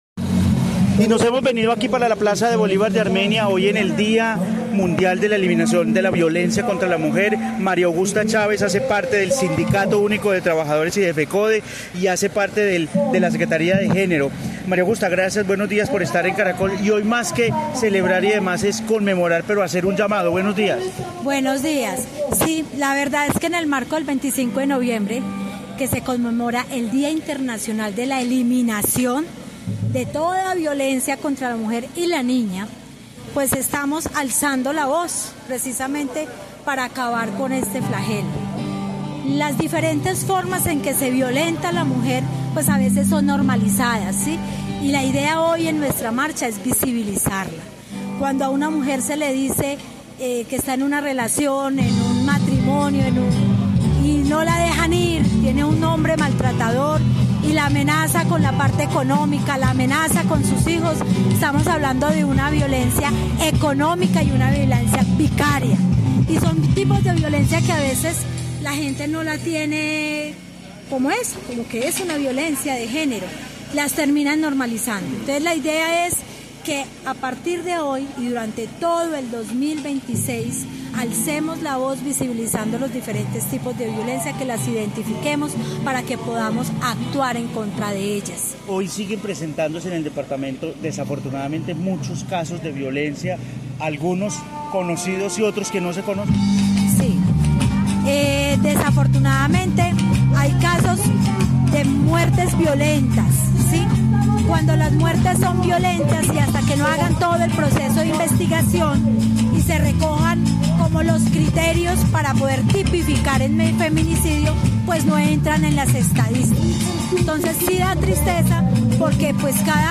En Caracol Radio Armenia hablamos con